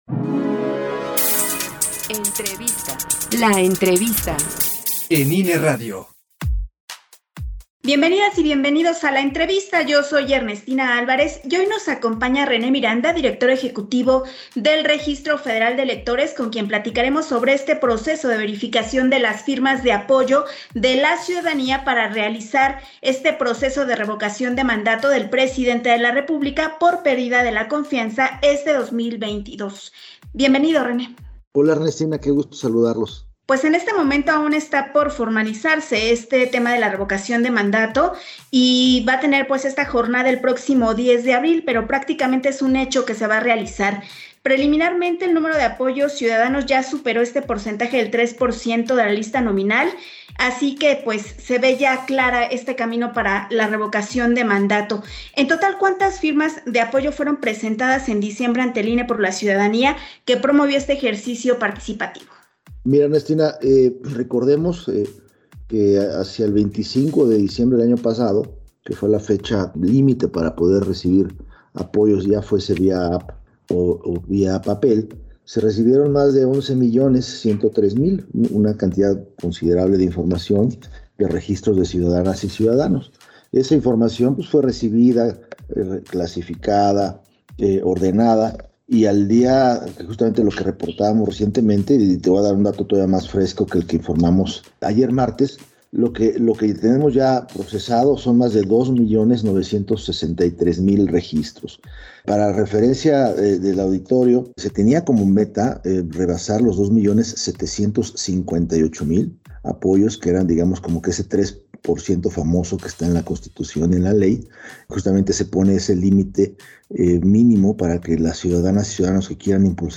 06_ENTREVISTA_RENE-MIRANDA_Revision-firmas-RM-19-enero-2022_CE